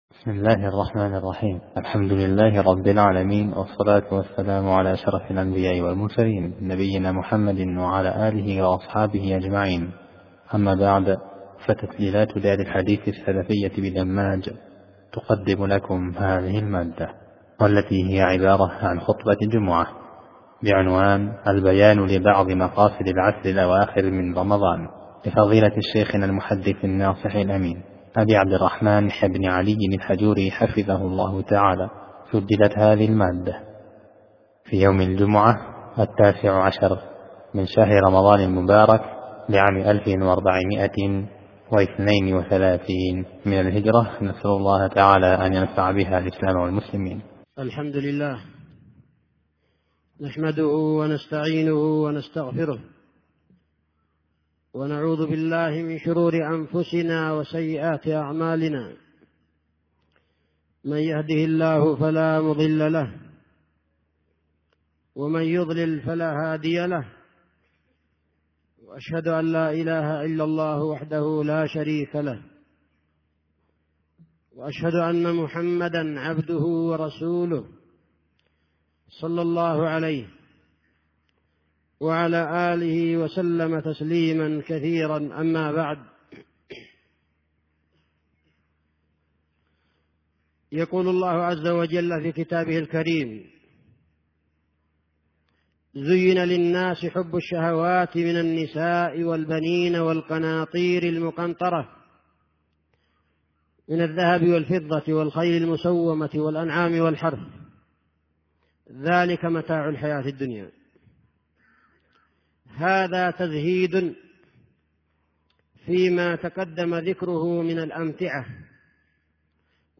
خطبة جمعة: (البيان لبعض مقاصد العشر الأواخر من رمضان)